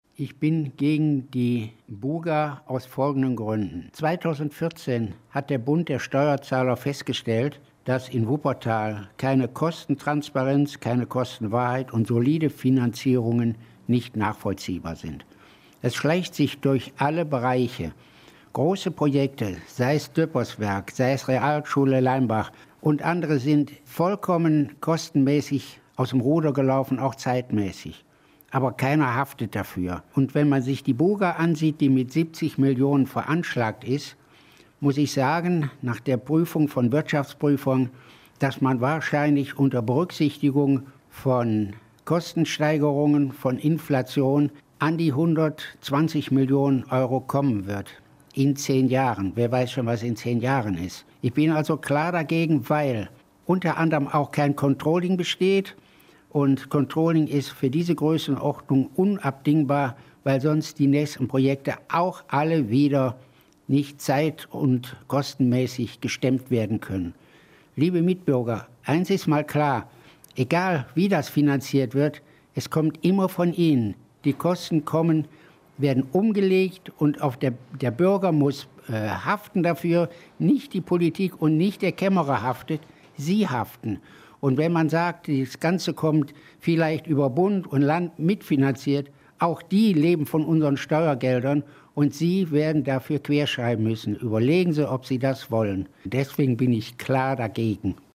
Gastkommentar